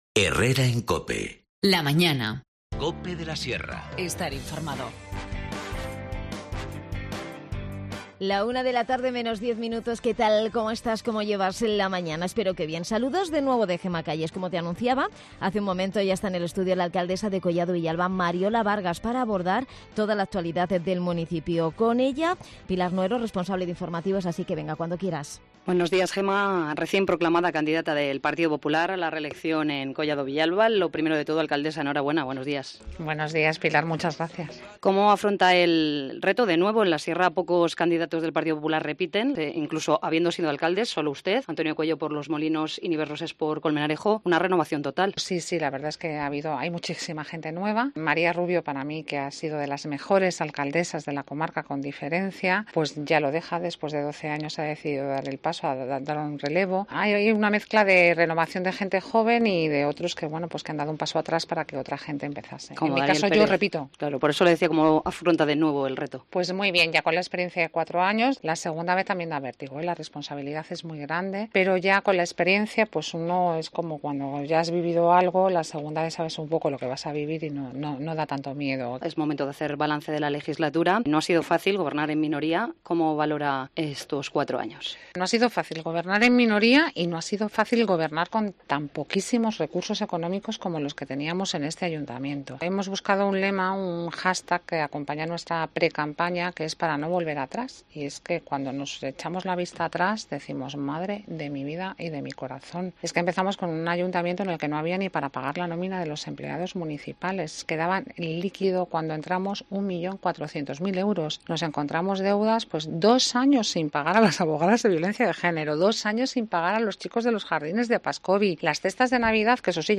La alcaldesa de Collado Villalba, Mariola Vargas, ha repasado este miercoles la actualidad del municipio en su visita a nuestros estudios.